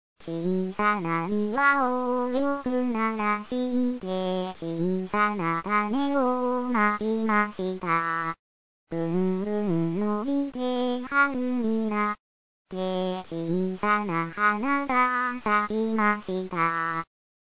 段階Ａ導入分類アクションソング
アカペラを聴く